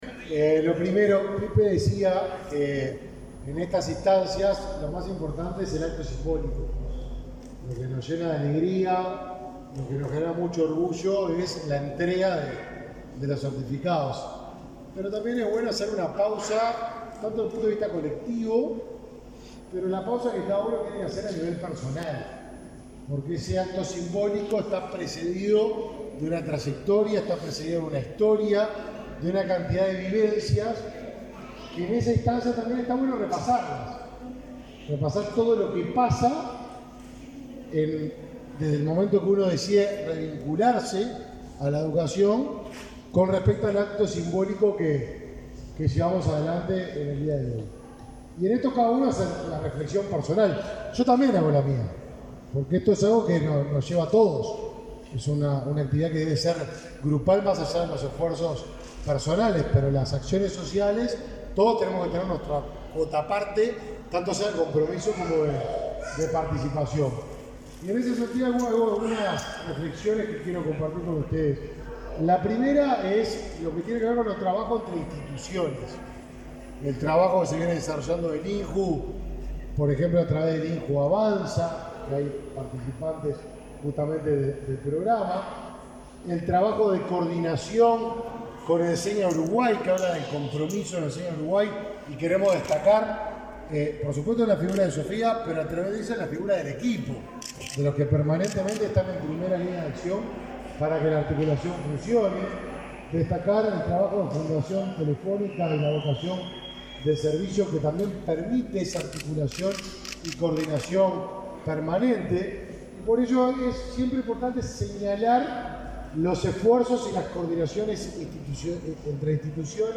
Palabras del ministro de Desarrollo Social, Martín Lema
Palabras del ministro de Desarrollo Social, Martín Lema 26/10/2023 Compartir Facebook X Copiar enlace WhatsApp LinkedIn Este jueves 16 en el Instituto Nacional de la Juventud (INJU), el ministro de Desarrollo Social, Martín Lema, participó del acto de entrega de diplomas del programa Acredita, Ciclo Básico y Alfabetización Digital.